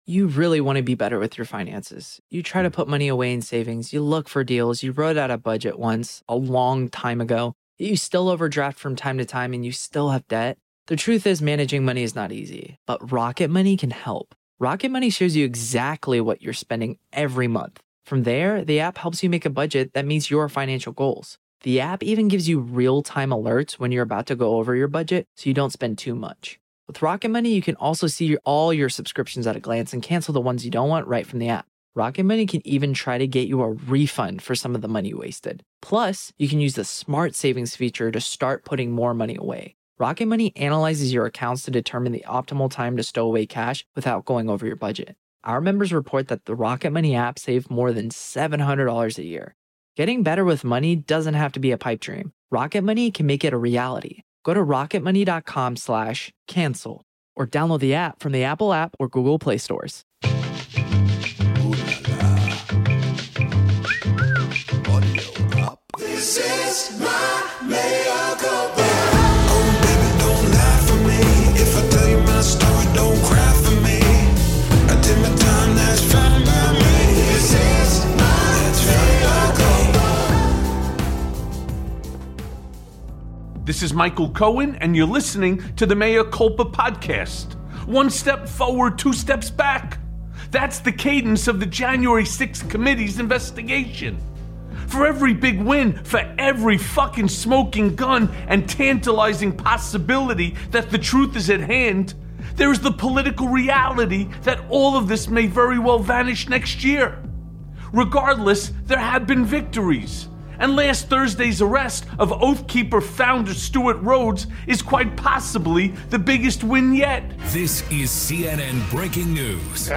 Inside the Oath Keepers Terrifying Jan 6th Plot+ A Conversation with Rick Wilson
Michael breaks down the Oath Keepers January 6th plot and how it was far worse than anybody realized. The Lincoln Project's Rick Wilson joins Mea Culpa to tell us what it all means.